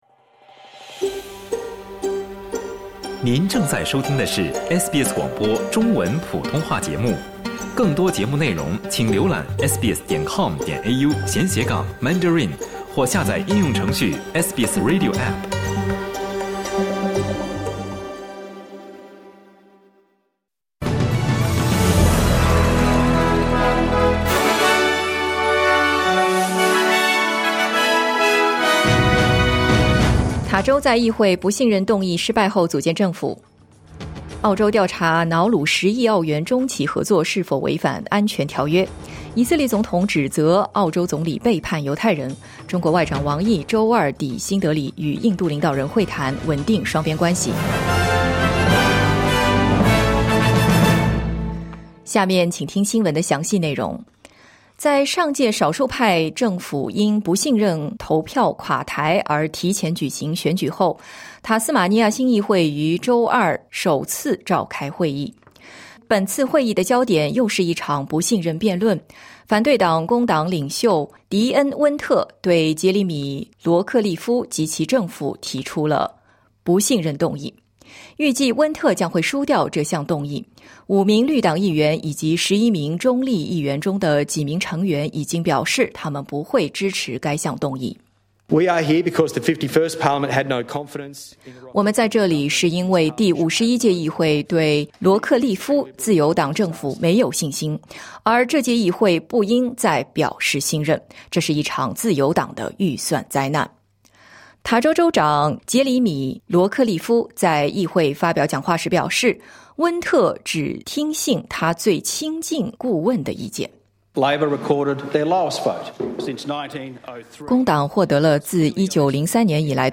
SBS早新闻（2025年8月20日）